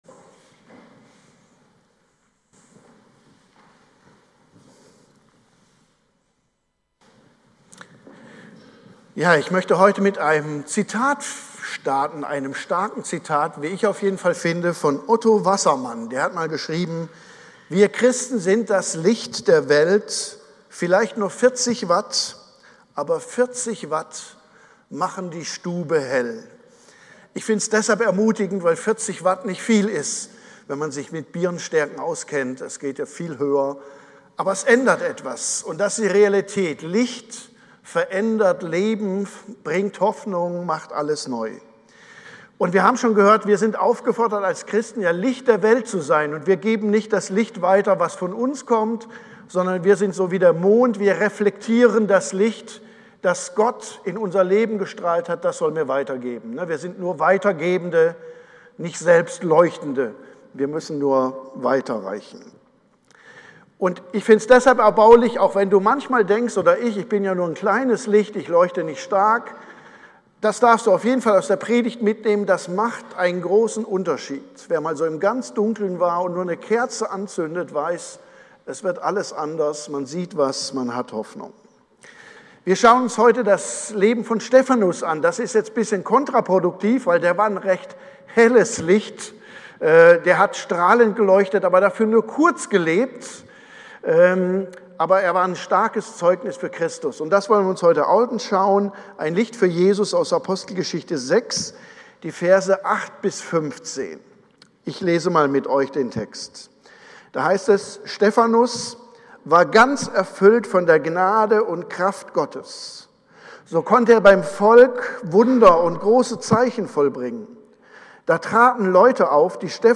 Predigt-am-26.01-online-audio-converter.com_.mp3